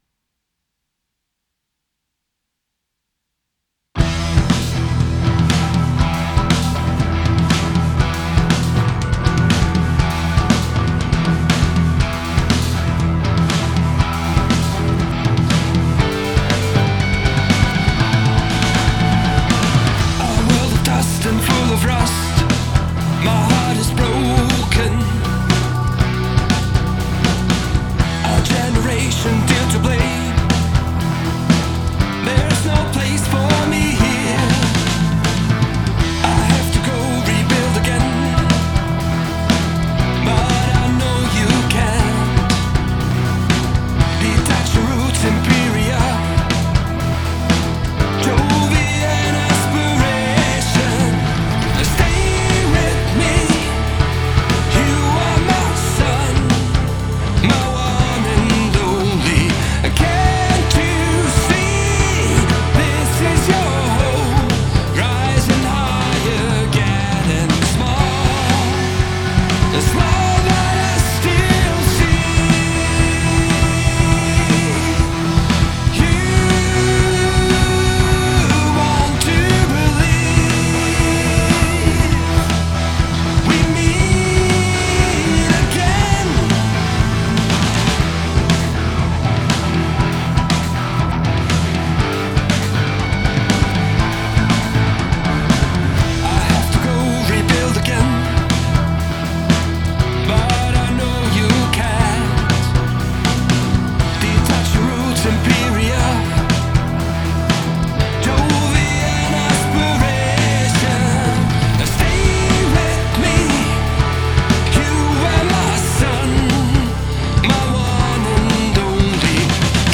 Edit: Irgendwie klingt diese Version seltsam - ich lade später nochmal eine saubere Version hoch